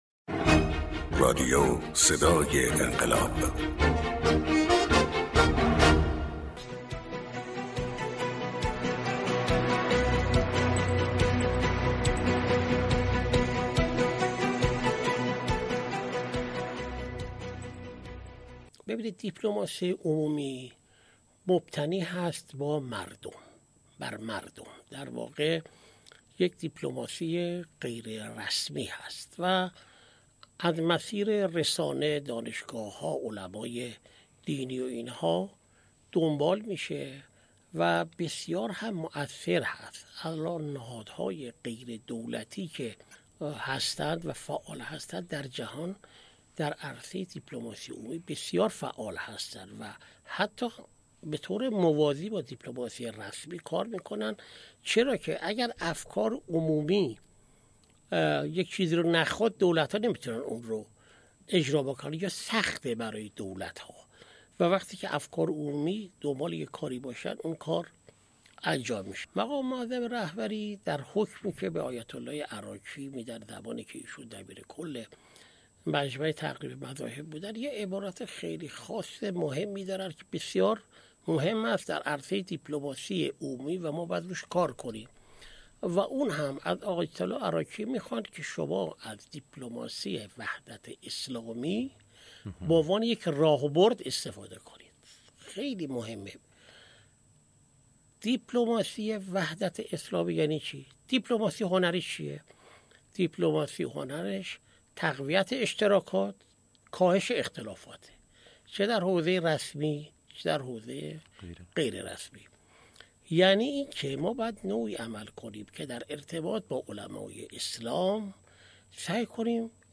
محسن پاک‌آیین، سفیر سابق کشورمان در آذربایجان: دیپلماسی فعال چیست ؟چطور می‌توان به آن دست پیدا کرد؟